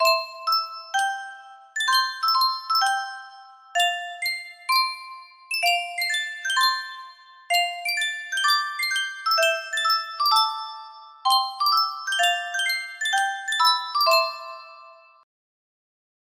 Yunsheng Music Box - Kamome no Suihei-san 2404 music box melody
Full range 60